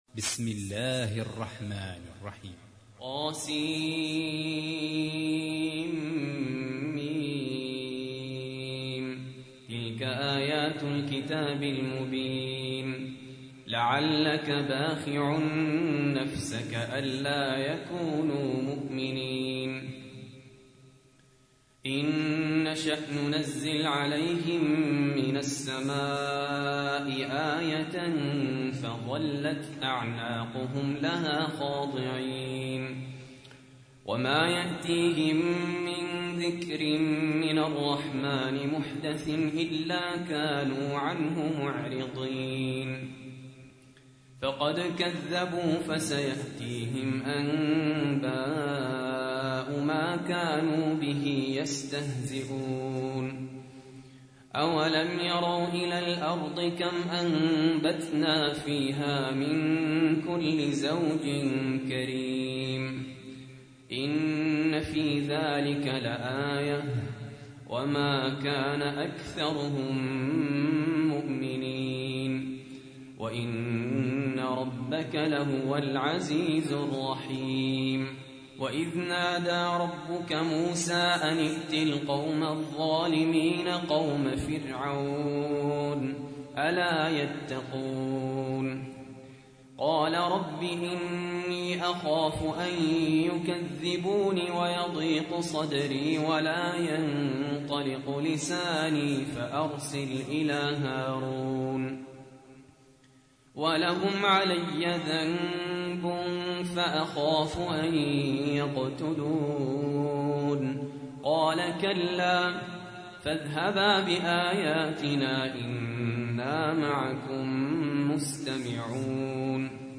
تحميل : 26. سورة الشعراء / القارئ سهل ياسين / القرآن الكريم / موقع يا حسين